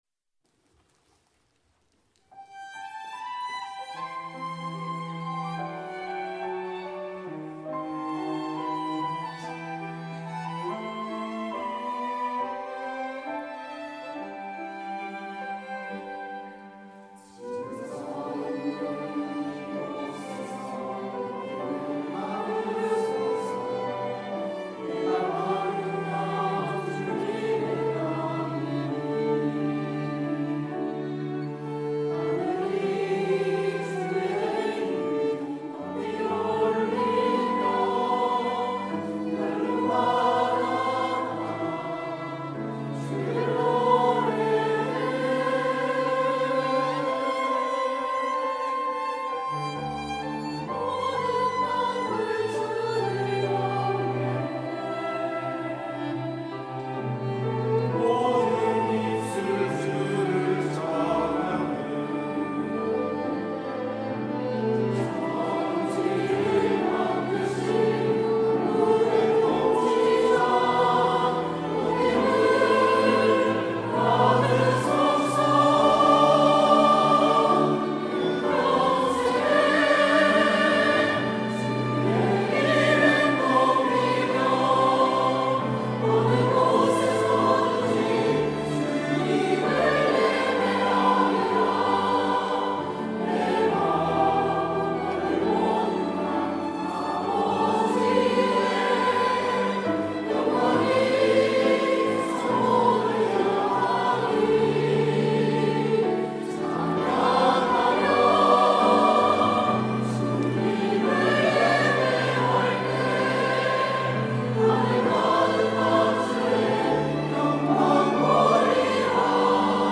1부 찬양대